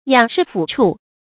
仰事俯畜 注音： ㄧㄤˇ ㄕㄧˋ ㄈㄨˇ ㄔㄨˋ 讀音讀法： 意思解釋： 上要侍奉父母，下要養活妻兒。泛指維持一家生活。